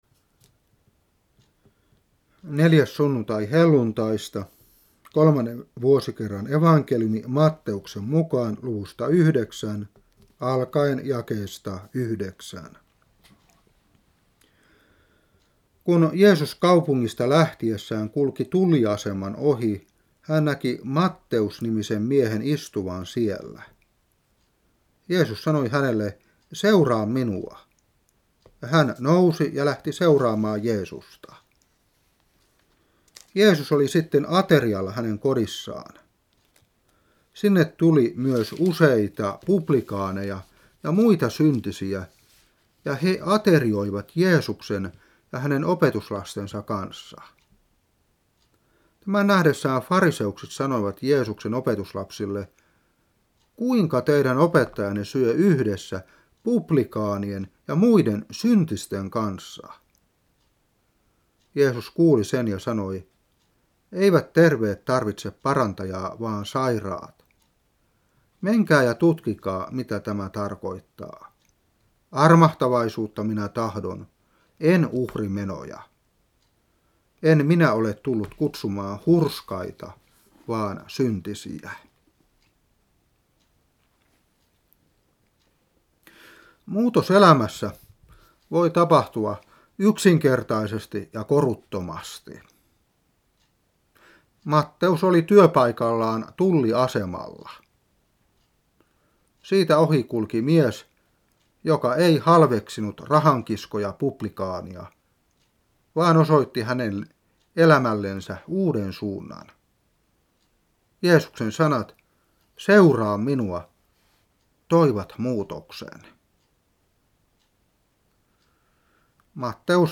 Saarna 1992-7.